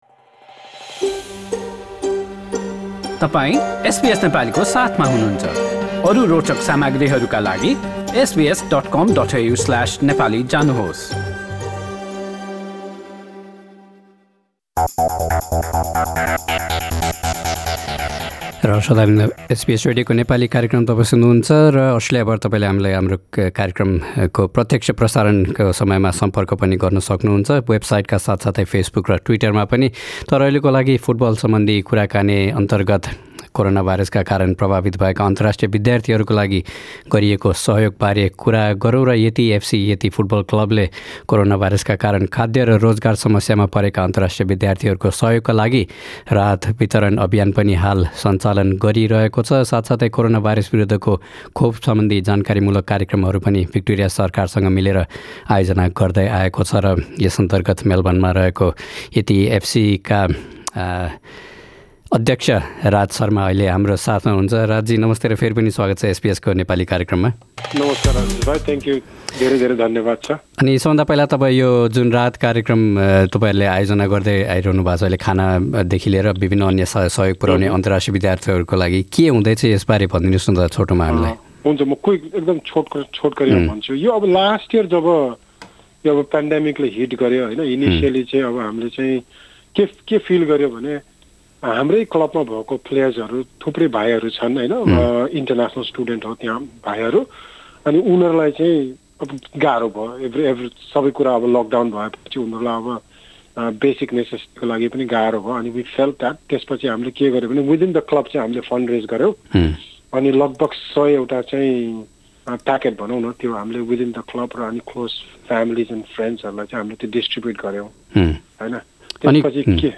हाम्रो कुराकानी सुन्नुहोस्: